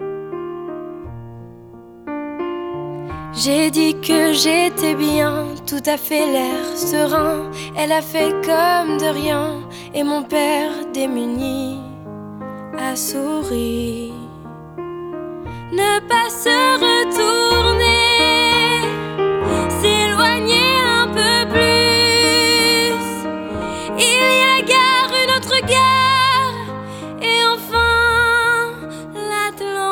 Genre: Musique francophone